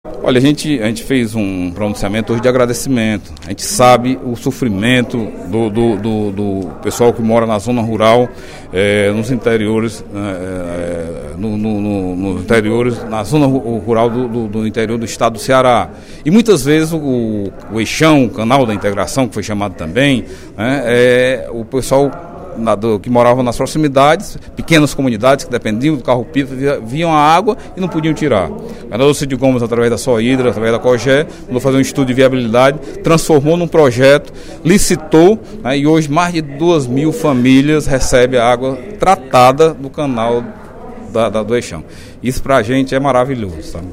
O deputado Delegado Cavalcante (PDT) elogiou e agradeceu, durante pronunciamento na sessão plenária desta quarta-feira (13/06), iniciativa do Governo do Estado de promover a ligação do Eixão das Águas com comunidades da região do Baixo Jaguaribe.